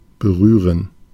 Ääntäminen
IPA: /bəˈʀyː.ʀən/